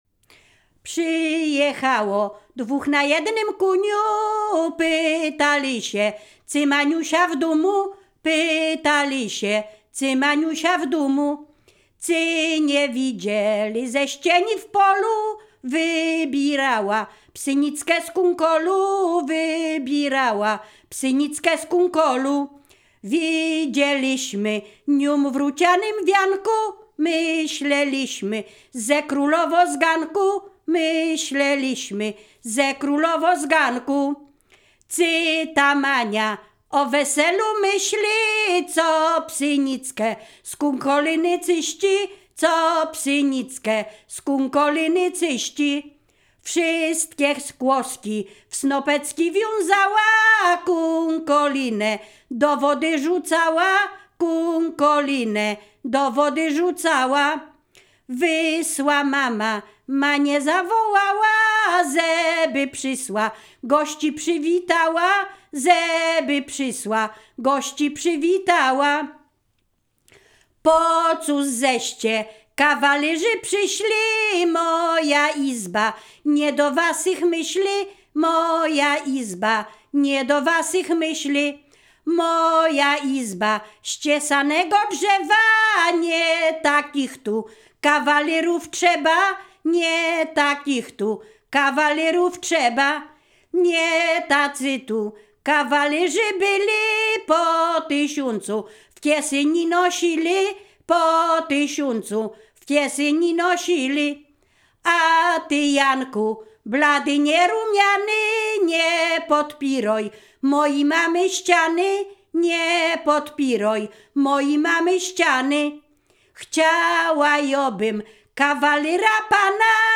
Ziemia Radomska
liryczne miłosne weselne